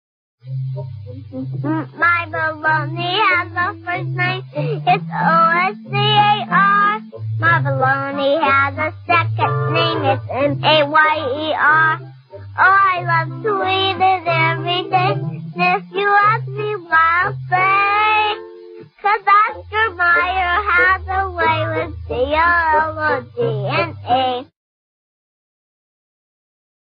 Here is a commercial i haven't heard in a long time